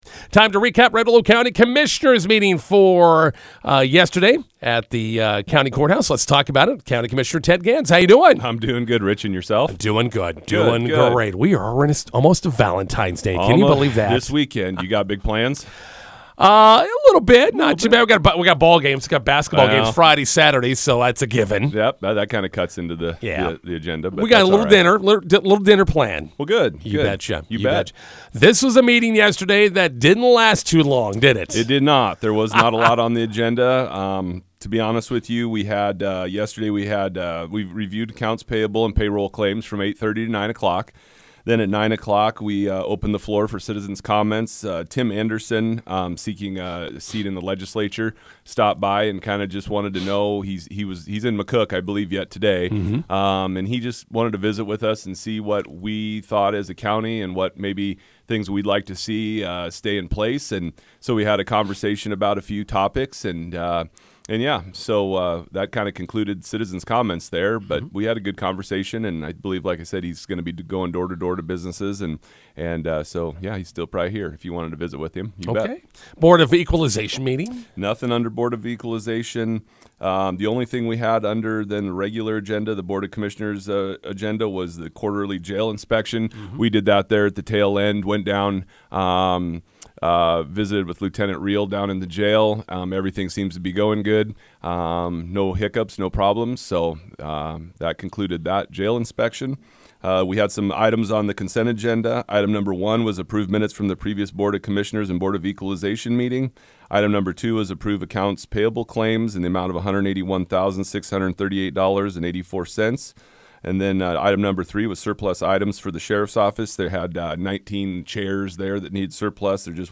INTERVIEW: Red Willow County Commissioners meeting recap with County Commissioner Ted Gans. | High Plains Radio